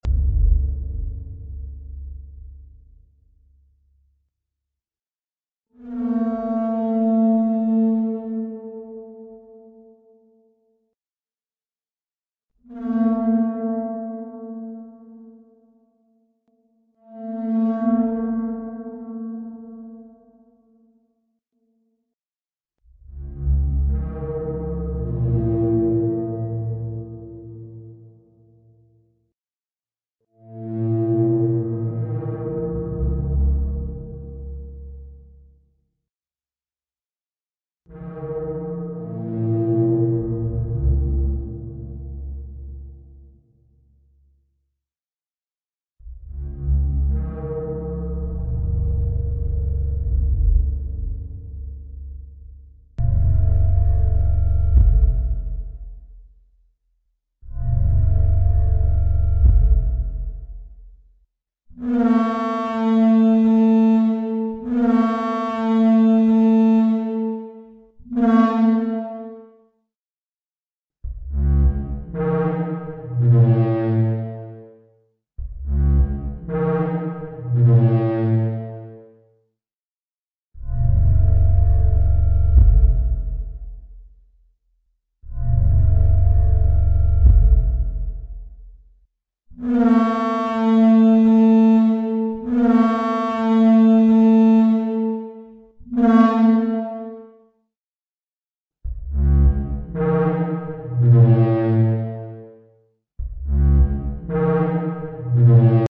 Apparently this is how Parasaurolophus sound effects free download
Apparently this is how Parasaurolophus sounded